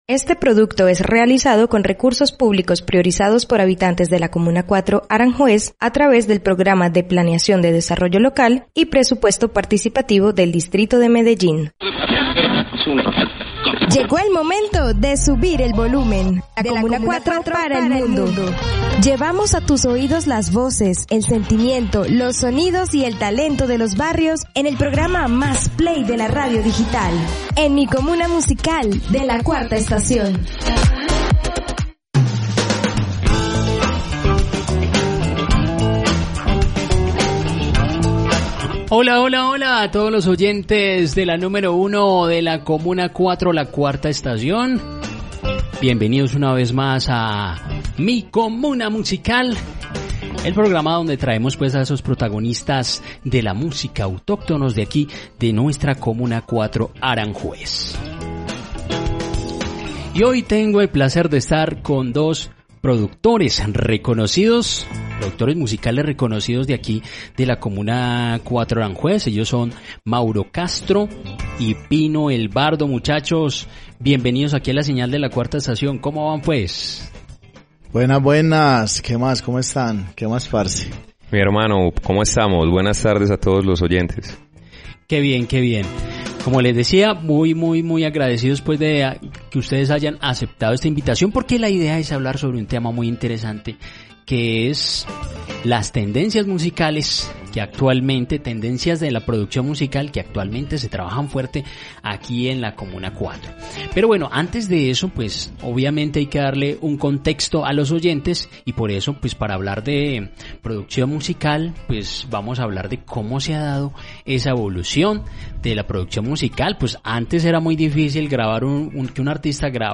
📻 En nuestro programa de Mi Comuna Musical, conversamos